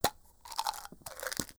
- Take painkillers and antbiotics has a sounds.